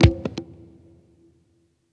PERCUSSN042_DISCO_125_X_SC3.wav